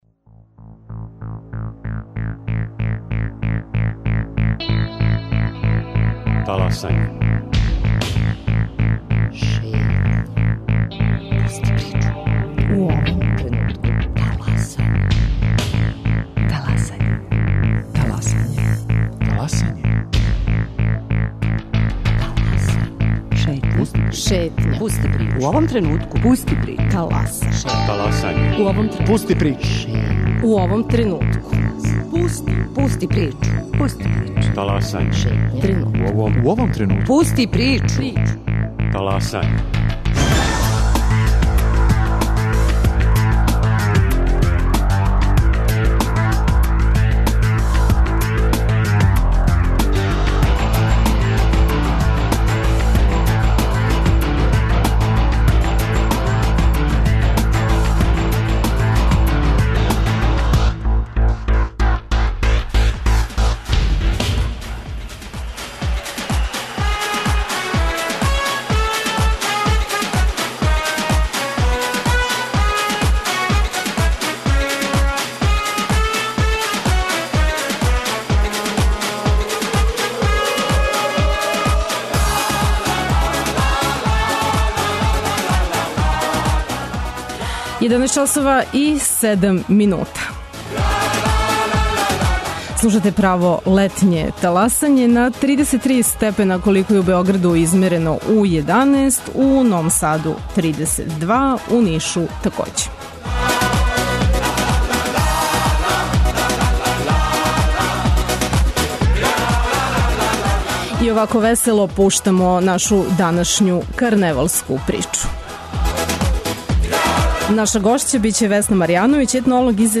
Зато ће нам се у разговору придружити и организатори карневала у Лесковцу, који је у току, карневала у Врњачкој бањи и београдског карневала бродова, који почињу за неколико дана, као и представници Европске федерације карневалских градова.